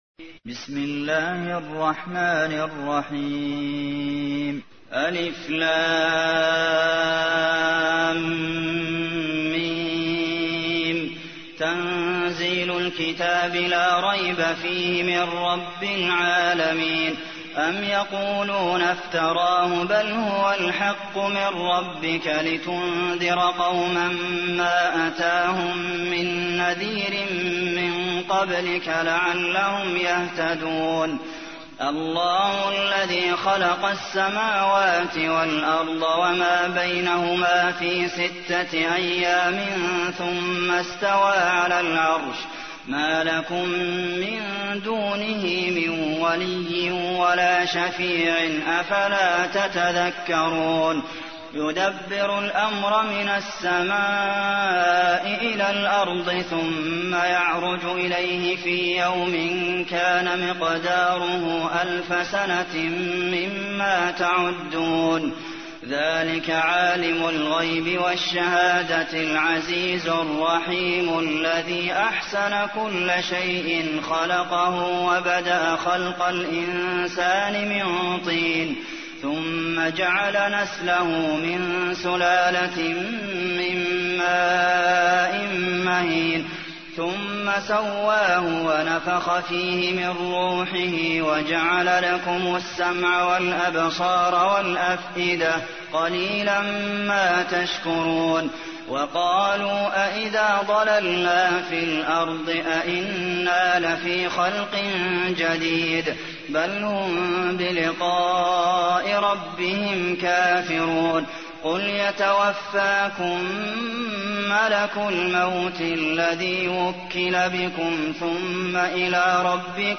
تحميل : 32. سورة السجدة / القارئ عبد المحسن قاسم / القرآن الكريم / موقع يا حسين